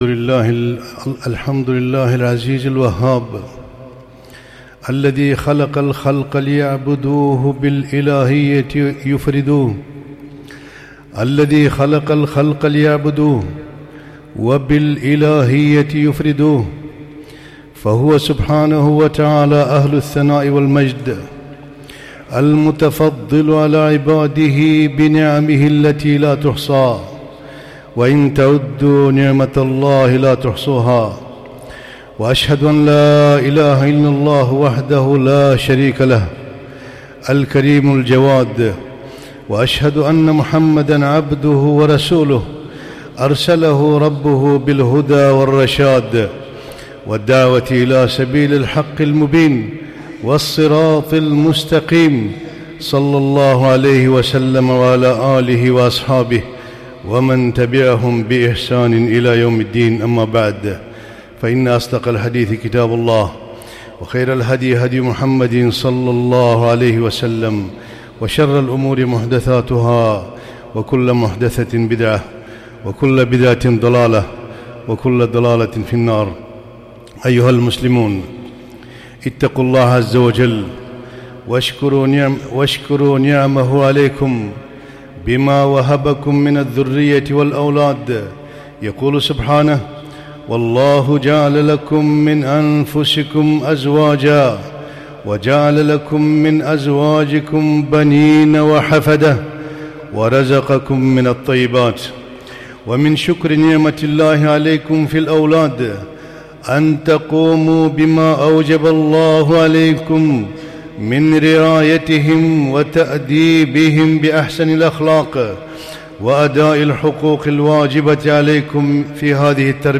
خطبة - تربية الأولاد أمانة ومسؤولية